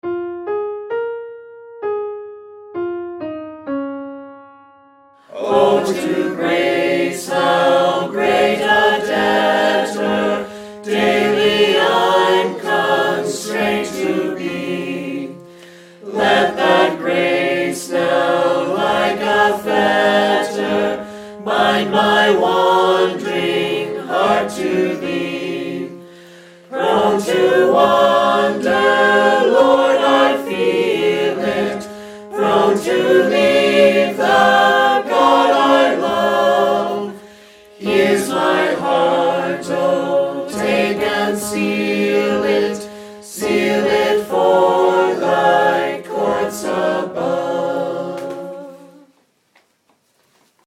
The files below use a recording made at our retreat in July, when a number of us learned the harmonies for this hymn.
The harmony part is always illustrated in the left channel by a piano.  Each file begins with a piano lead in that gives the final phrase of the melody so you can practice making the transition to whatever harmony part you’ve chosen.
Come-Thou-Fount-Tenor.mp3